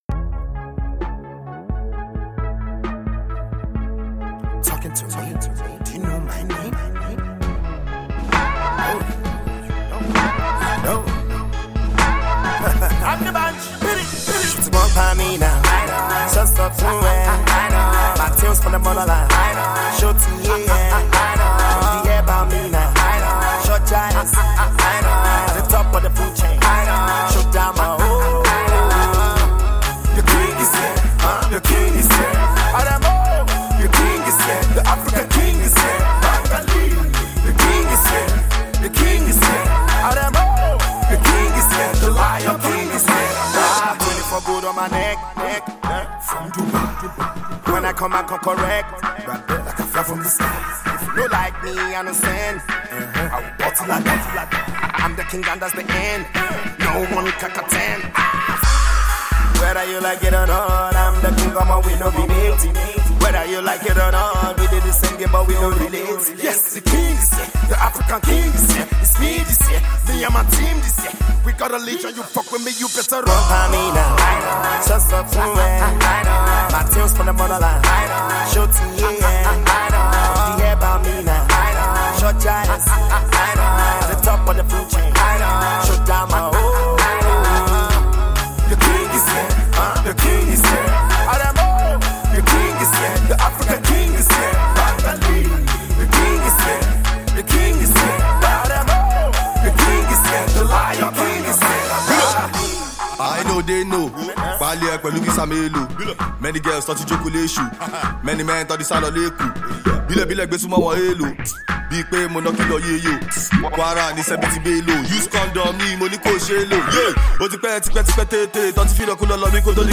teams up with South-African heavyweight rapper